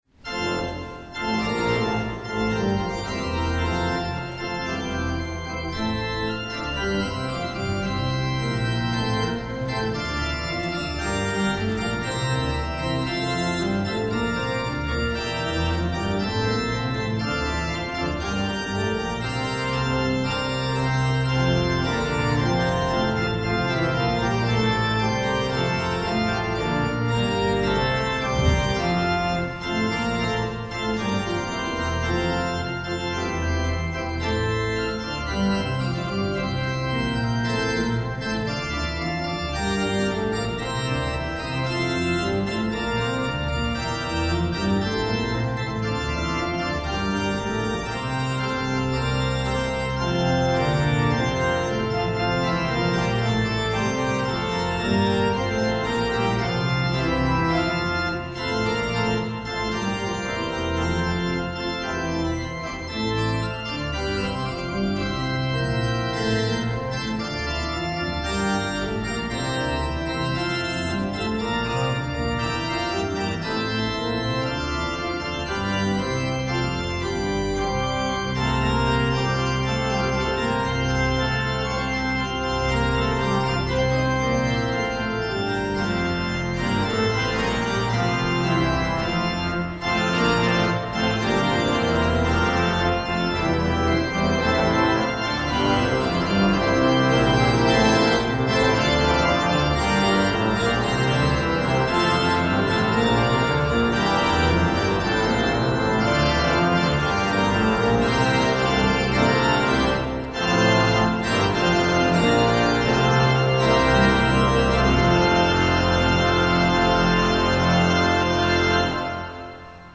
Hear the Bible Study from St. Paul's Lutheran Church in Des Peres, MO, from November 24, 2024.